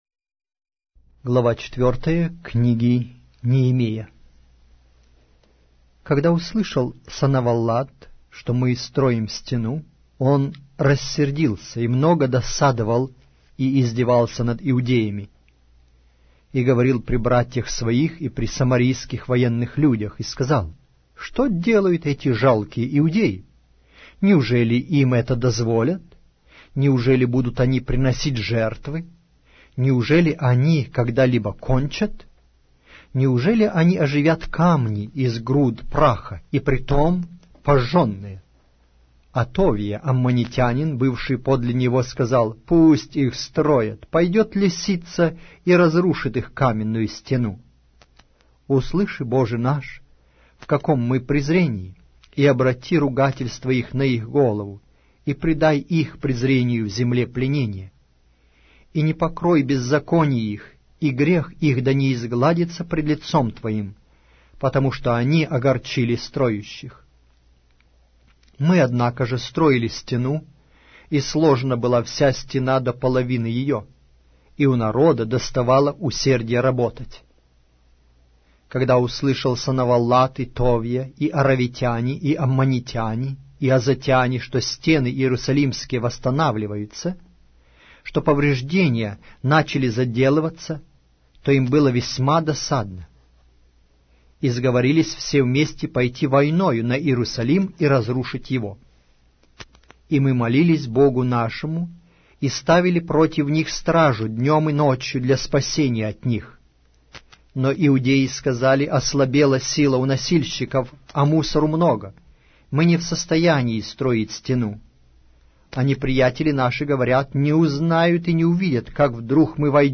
Аудиокнига: Пророк Неемия